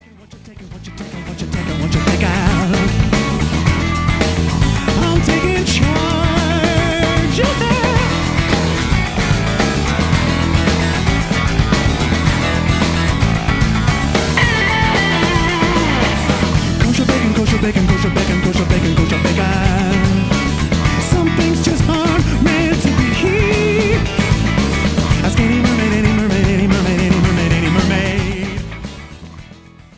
Modern Jewish Rock